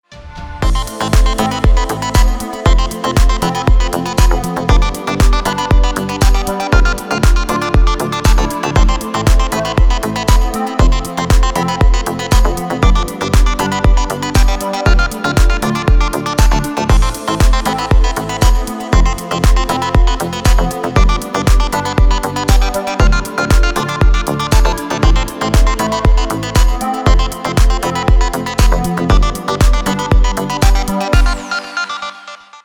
Deep House рингтоны
Дип хаус на звонок